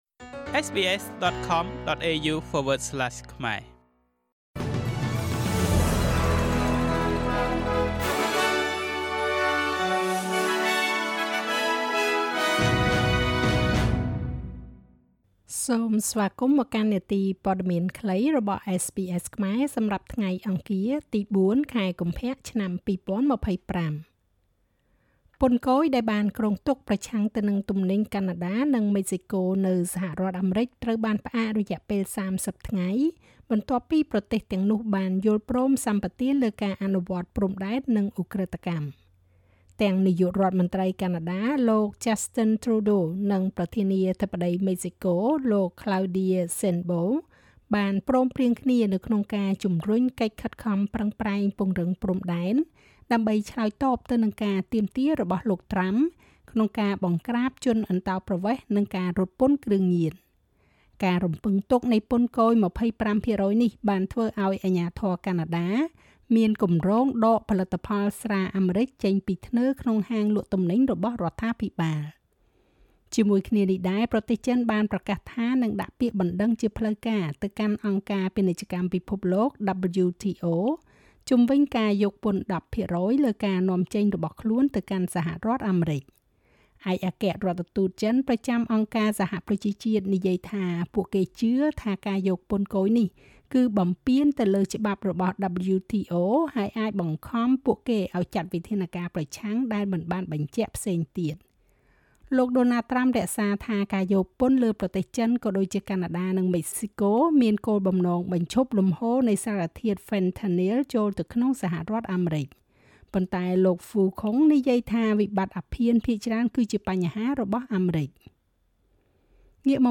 នាទីព័ត៌មានខ្លីរបស់SBSខ្មែរ សម្រាប់ថ្ងៃអង្គារ ទី៤ ខែកុម្ភៈ ឆ្នាំ២០២៥